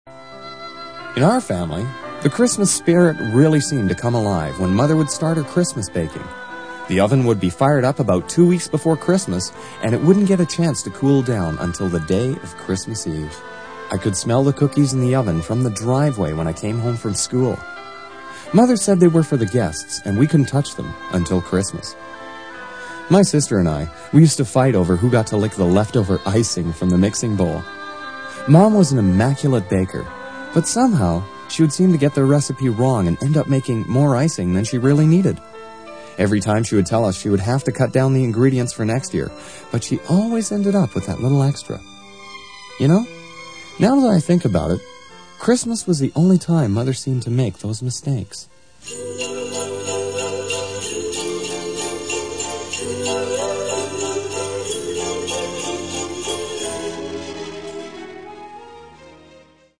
Christmas Vignitte
All of the above audition examples were written and/or Voiced here.